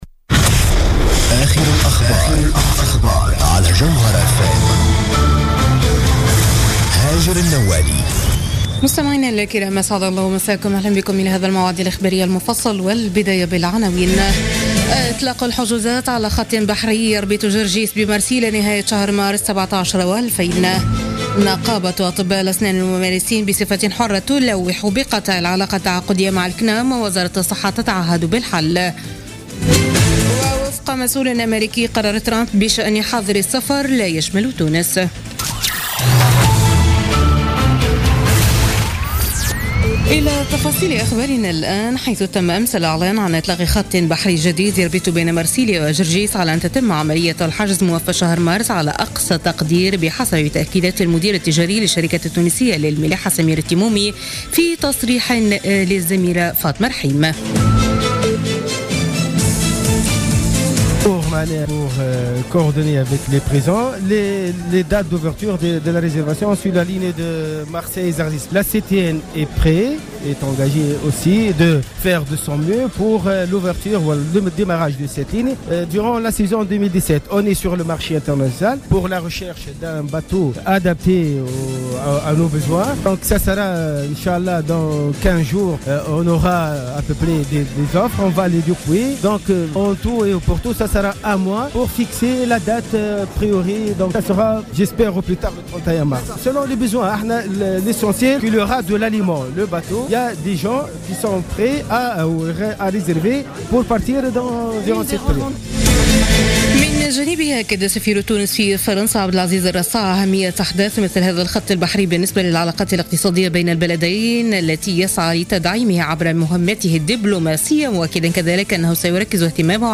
نشرة أخبار منتصف الليل ليوم الأحد 29 جانفي 2017